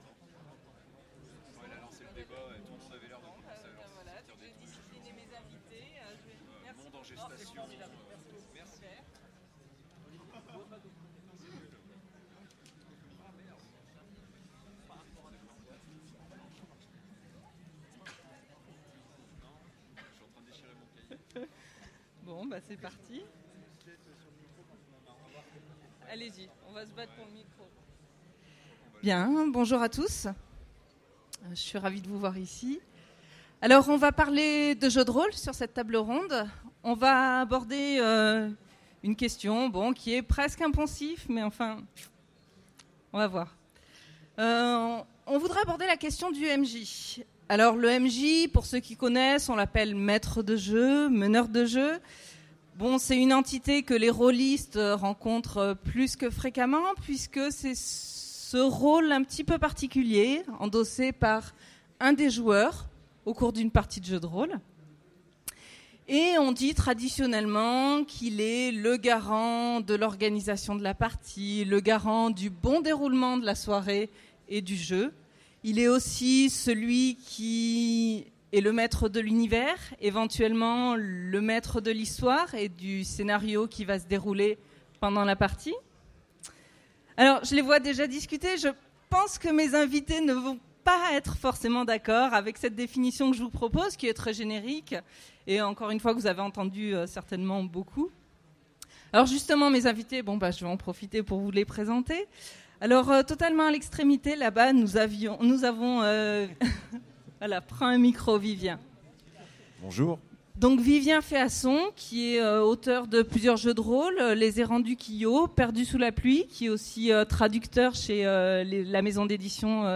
Utopiales 2015 : Conférence Jeu de rôle